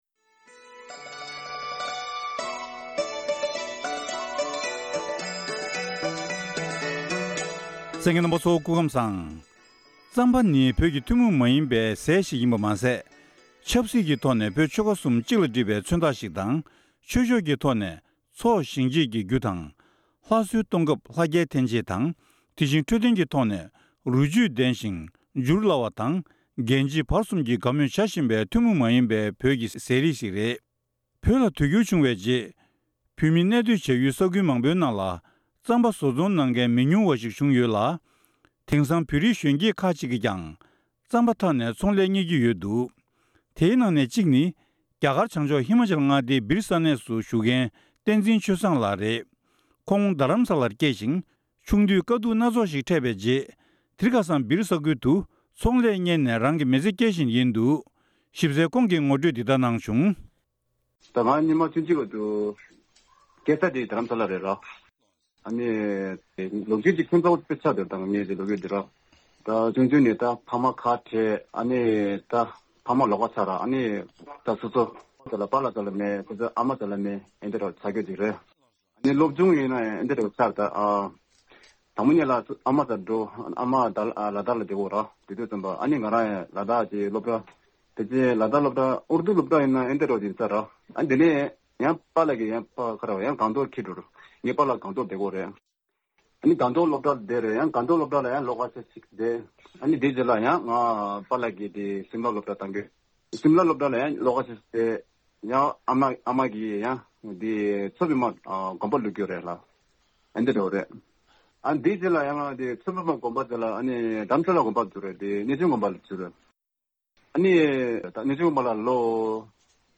གནས་འདྲི